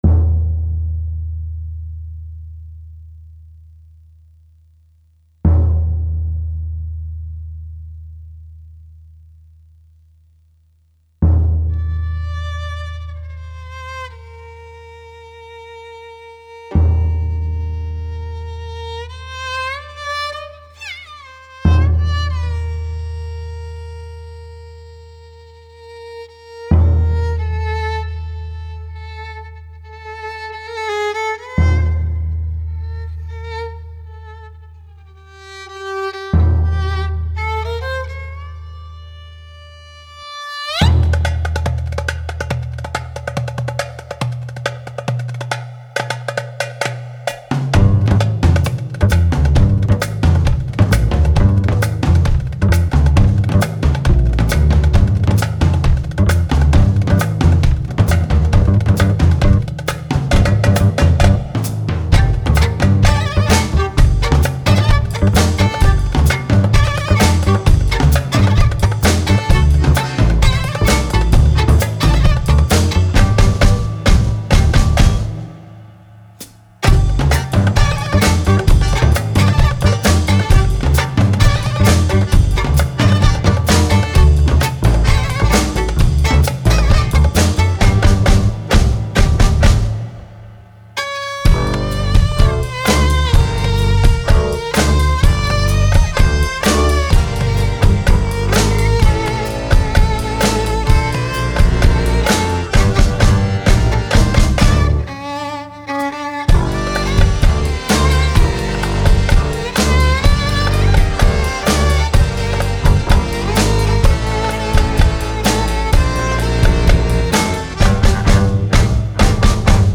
Guitar/D'rbukka
Violin/Viola
Accordian
Double Bass
Drums